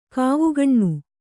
♪ kāvugaṇṇu